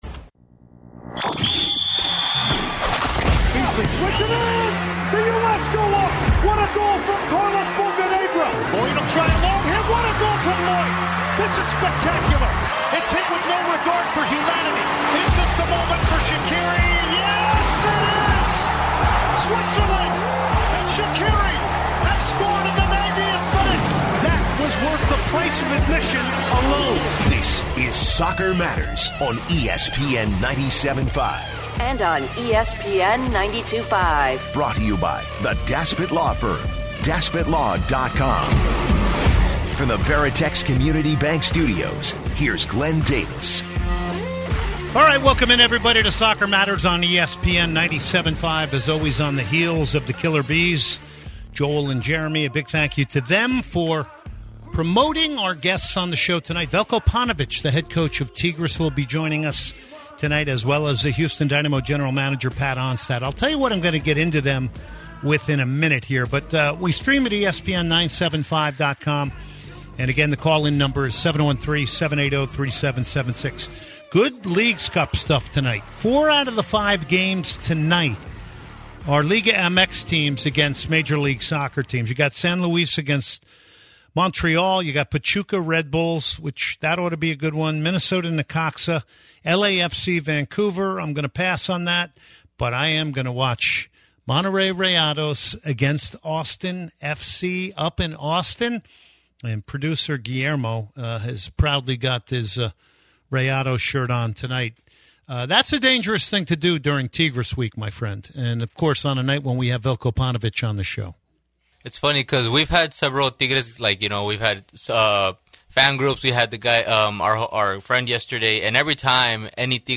He is then joined by Tigres coach, Veljko Paunović, for an interview as they will be playing both League Cup group stage matches in Houston.